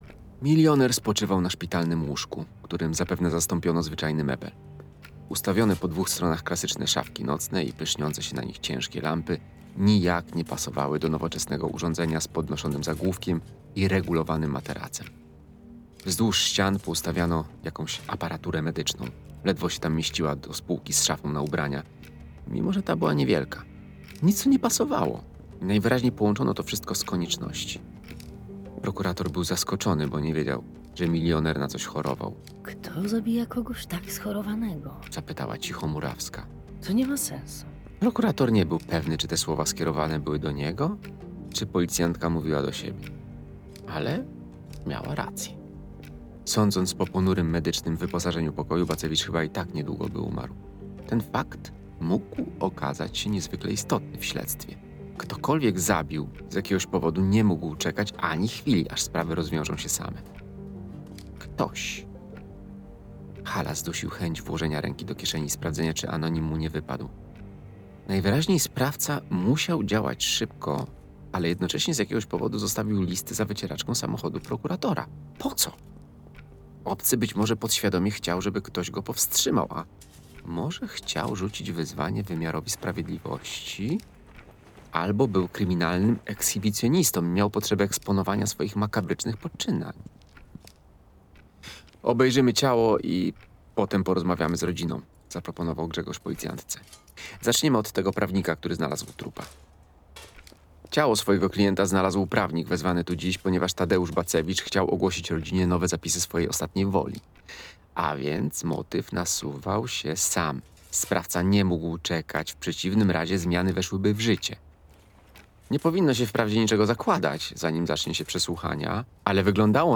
Nic takiego - Katarzyna Puzyńska - audiobook